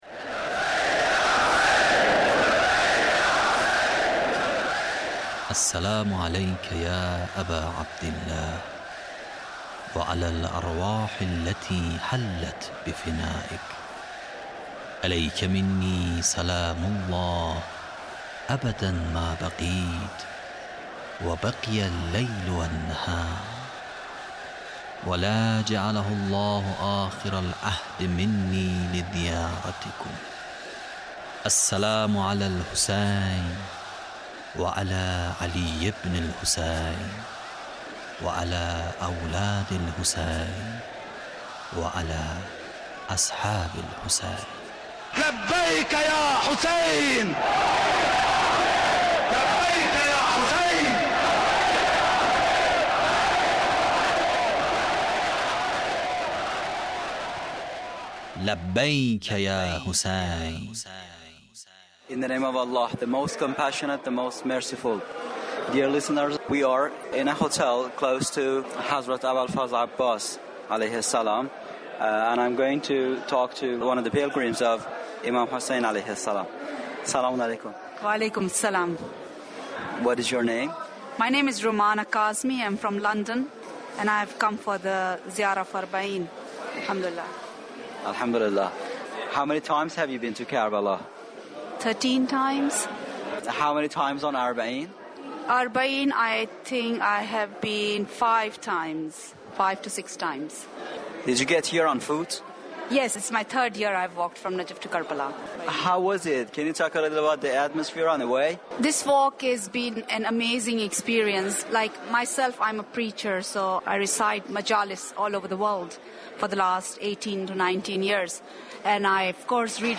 Interview with pilgrim of imam hussain (PART3)